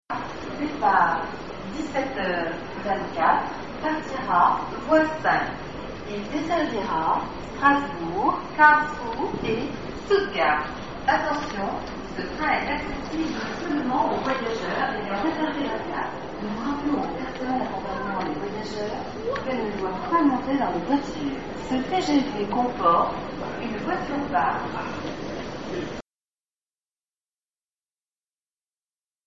Noch 8 Minuten, Gepäck, Taschen mit den in Paris gekauften Büchern unterm Arm und dem Mikro laufe ich zum Wagen 15 und höre, was der Lautsprecher diesmal den Reisenden rät:
tgv-depart.mp3